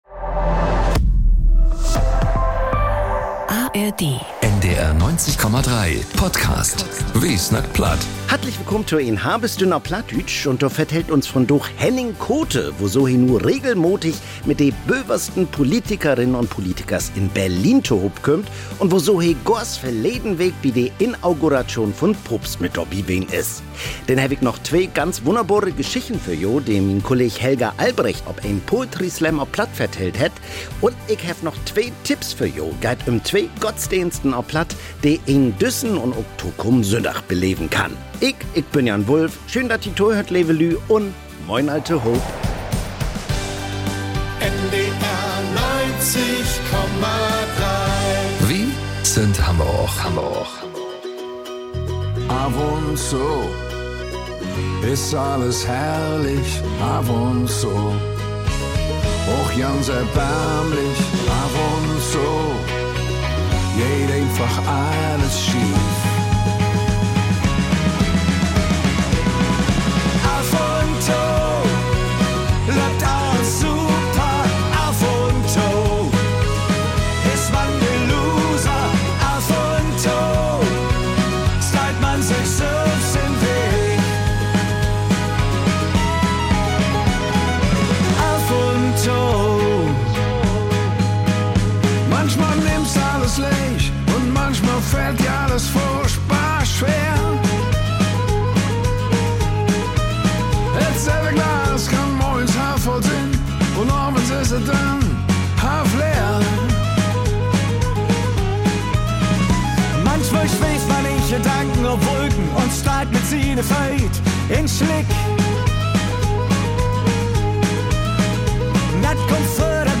NDR 90,3 bringt de Mudderspraak vun Norddüütschland ok in´t würkliche Leven to´n Klingen - mit Reportagen un Musik op Plattdüütsch/Plattdeutsch.